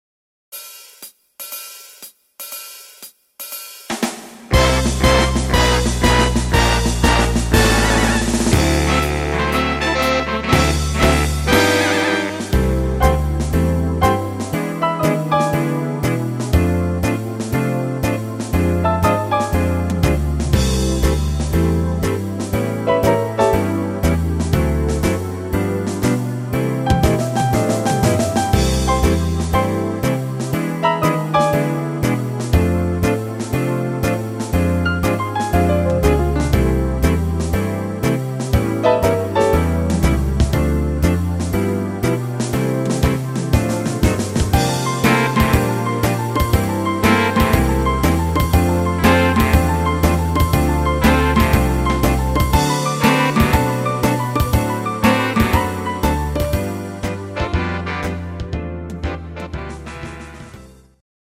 Rhythmus  Slow Swing
Art  Instrumental Allerlei, Jazz, Standard